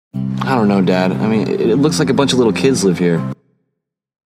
特に映画・ドラマの音声は、英語のニュースやTEDのスピーチなどとは段違いの難しさです（上の音声もドラマの一節です）。